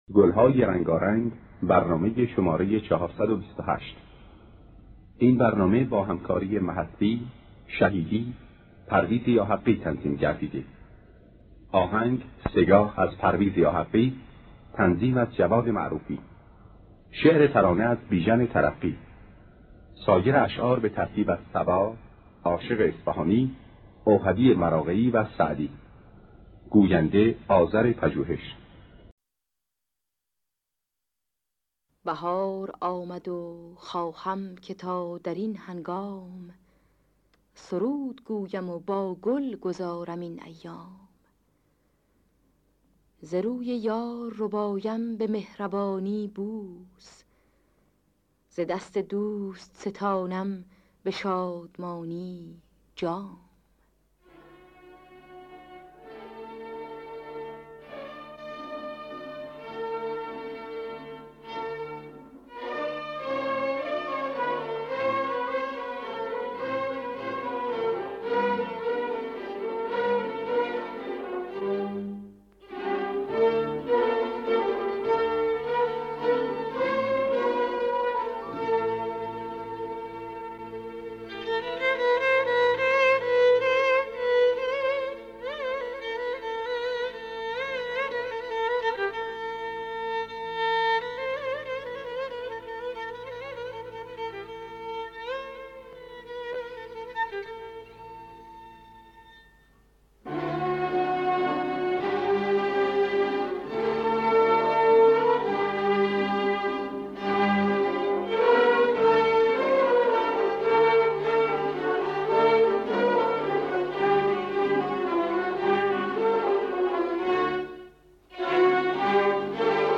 در دستگاه سه‌گاه